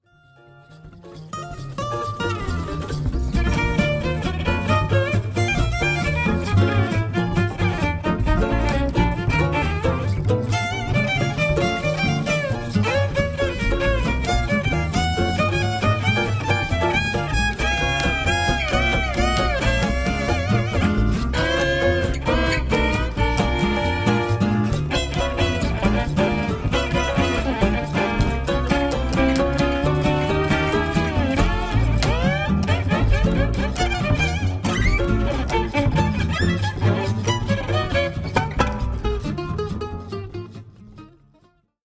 When I got back, this band needed a fiddle player.